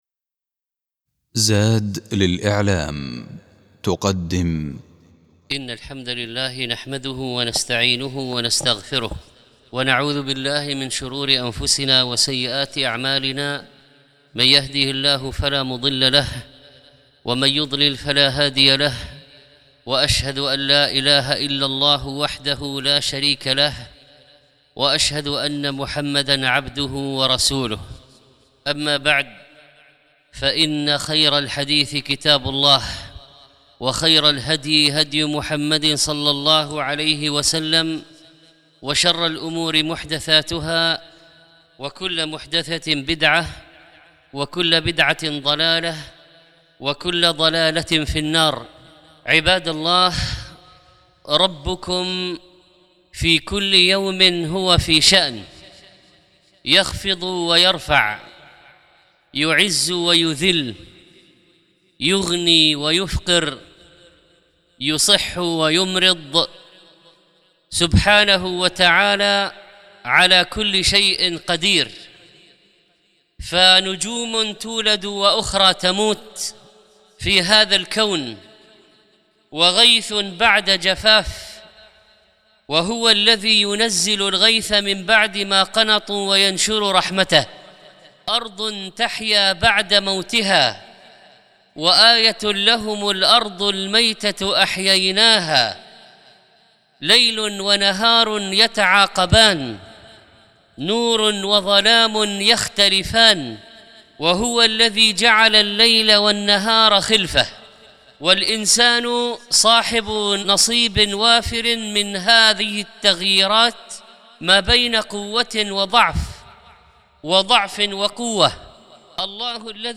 الخطبة الأولى سنة التغيير أهمية الصدق في التغيير